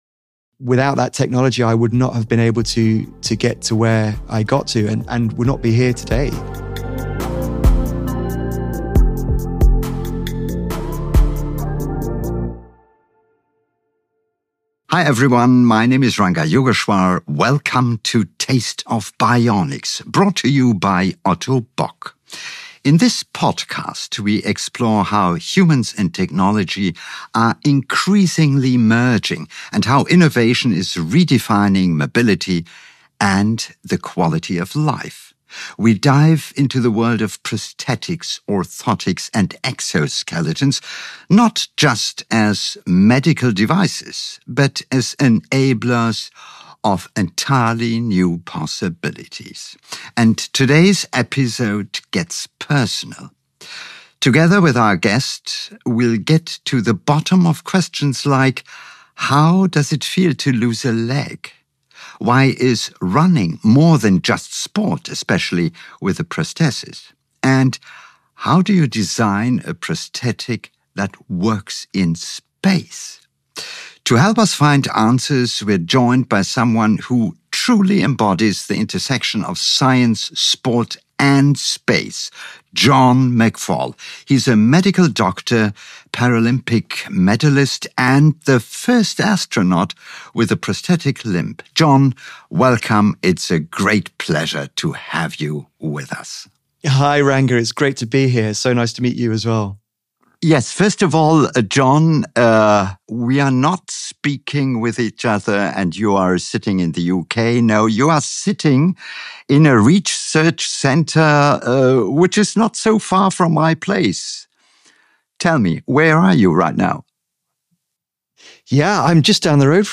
In this episode of Taste of Bionics, host Ranga Yogeshwar speaks with John McFall.